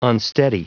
Prononciation du mot unsteady en anglais (fichier audio)
Prononciation du mot : unsteady